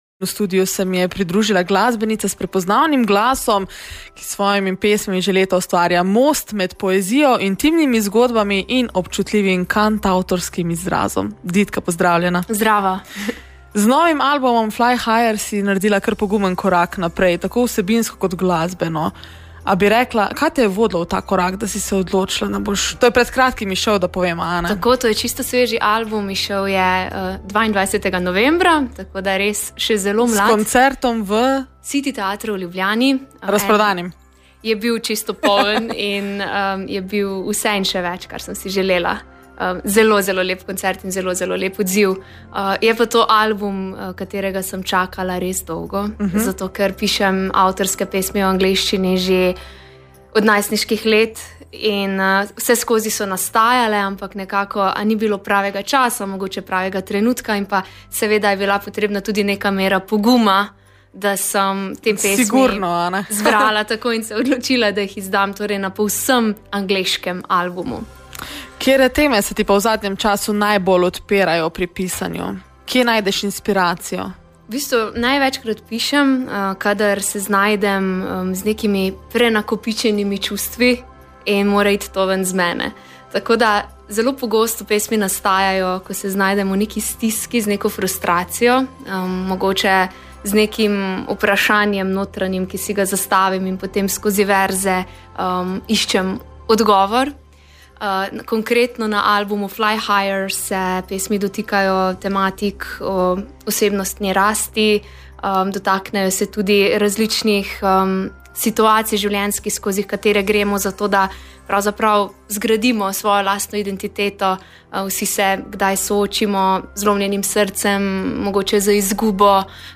V decembrskem vzdušju nas je na Koroškem radiu obiskala Ditka, ki je s seboj prinesla svoj najnovejši album – tudi v vinilni izdaji, ki mu daje prav poseben pečat.